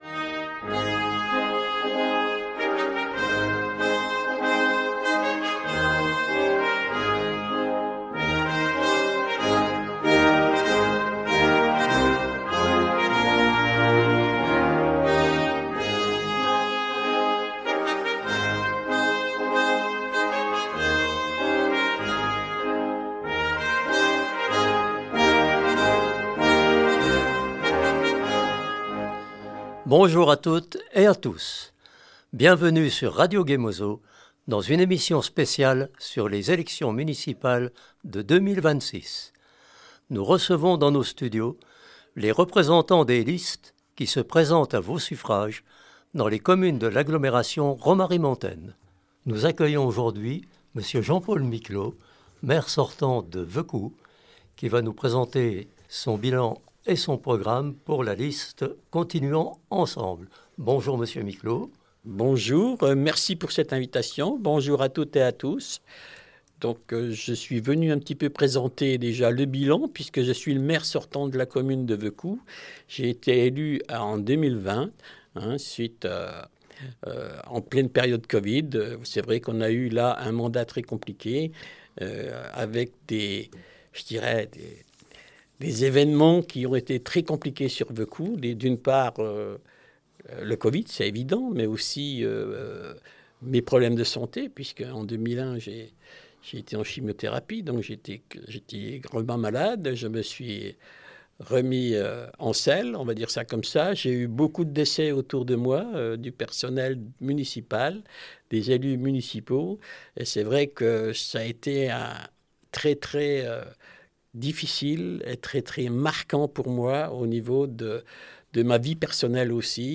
À l’approche des élections municipales, RGM donne la parole aux candidats.